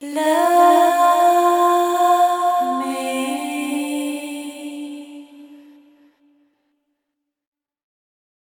Category 😂 Memes
female love sing vocal woman sound effect free sound royalty free Memes